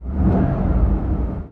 CosmicRageSounds / ogg / general / cars / rev5.ogg